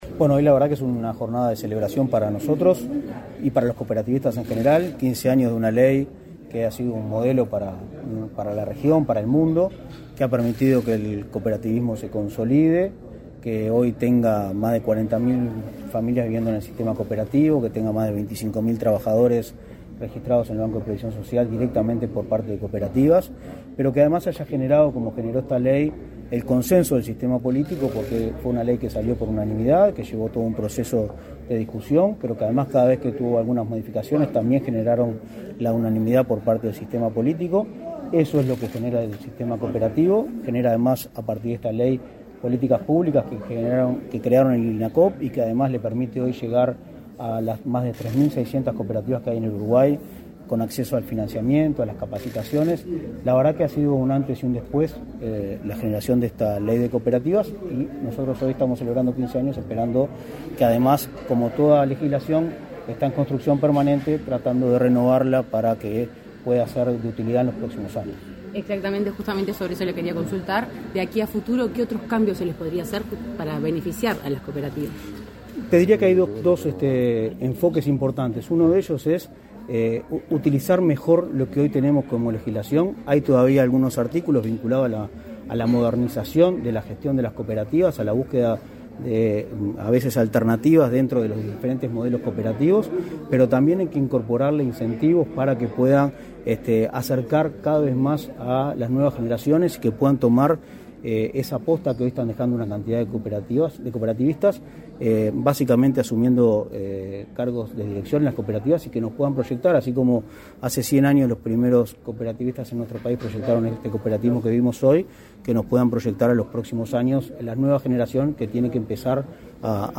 Declaraciones a la prensa del presidente de Inacoop, Martín Fernández
El Instituto Nacional del Cooperativismo (Inacoop) realizó, este 31 de octubre, un acto por el 15.° aniversario de la promulgación de la Ley General de Cooperativas n.° 18.407. En la oportunidad, el presidente del instituto, Martín Fernández, realizó declaraciones a la prensa.